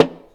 acoustic household percussion sound effect free sound royalty free Memes